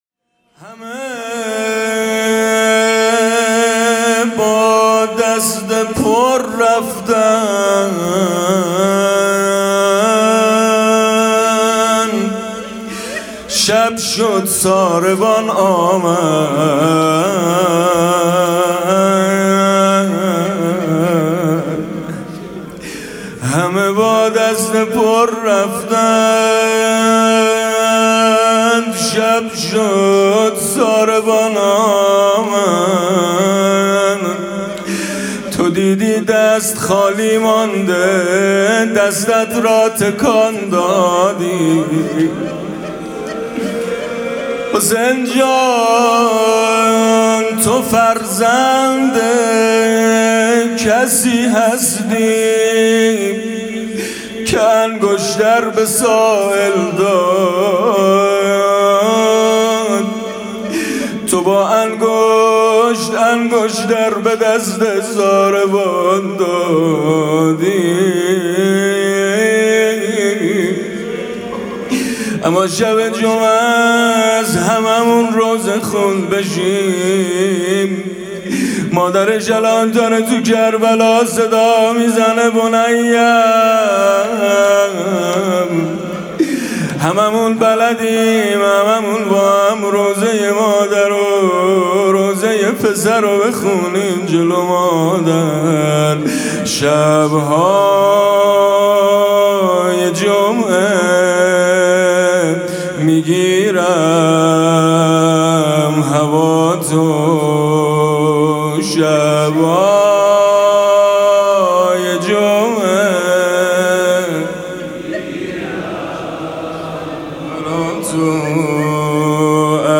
مناسبت: روضه‌ی هفتگی و قرائت زیارت امام زمان (عج)